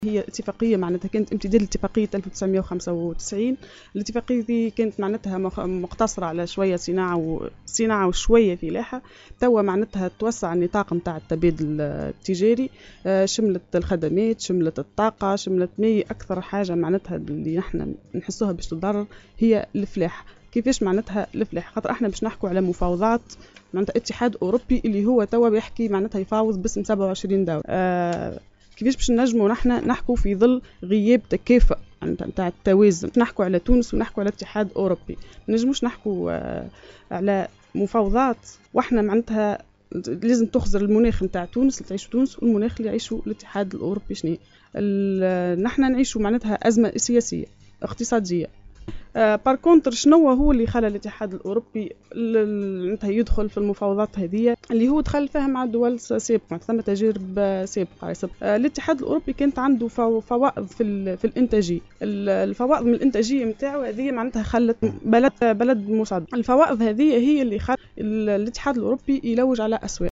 برنامج حديث قصارنية على موجات السيليوم أف أم